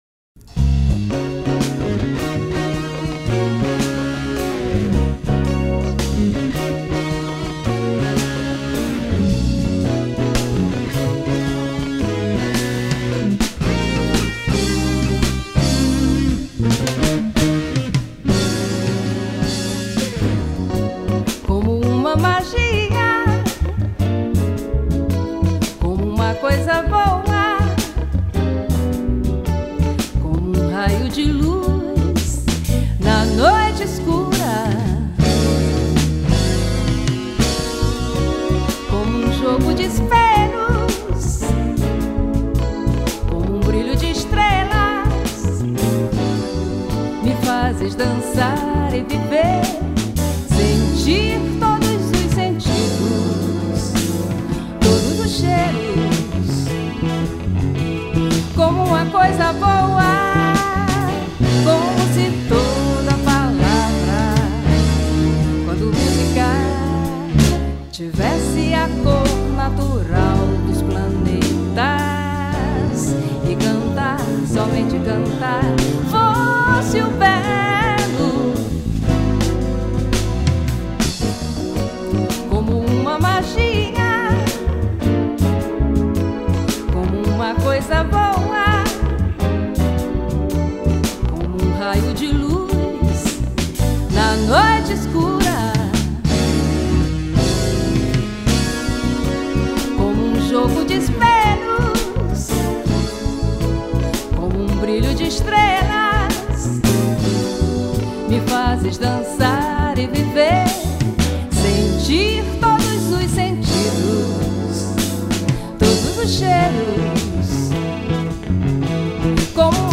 96   05:45:00   Faixa: 0    Jazz
Piano Elétrico, Teclados
Baixo Elétrico 6
Guitarra
Bateria